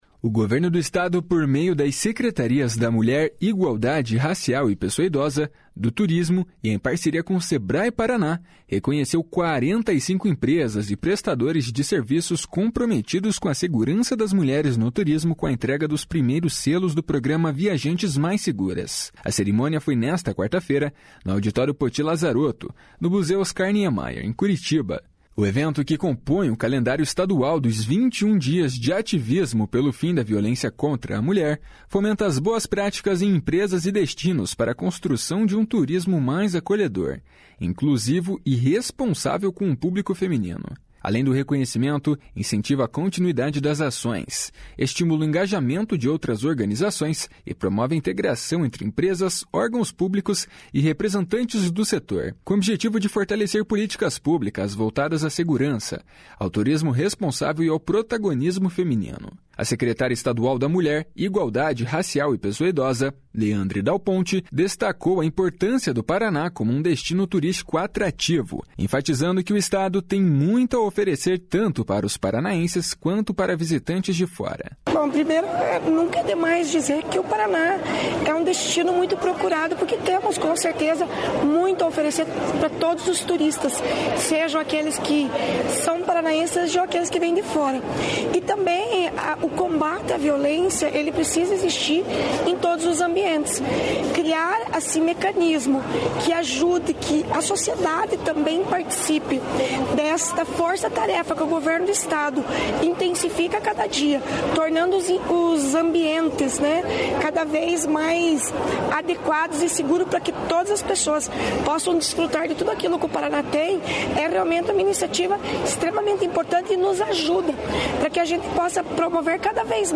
A cerimônia foi nesta quarta-feira, no auditório Potty Lazzaroto, do Museu Oscar Niemeyer, em Curitiba.
Márcio Nunes, secretário estadual do Turismo, participou da solenidade e reafirmou o compromisso do Estado com o tema. // SONORA MÁRCIO NUNES //